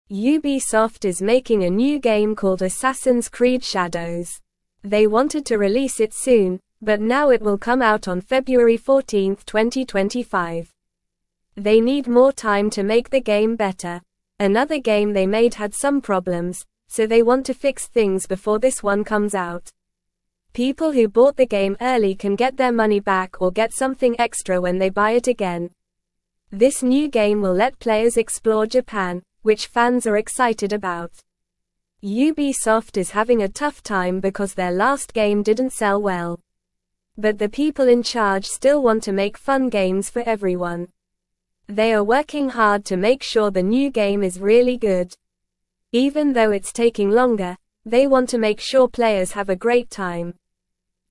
Normal
English-Newsroom-Beginner-NORMAL-Reading-Ubisoft-delays-new-game-to-make-it-better.mp3